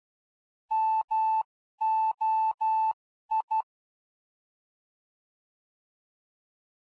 Fox hunt beacons are identified by morse code signals.
Morse code identifiers of the foxes
2 MOI — — — — — ∙ ∙ [ogg][mp3]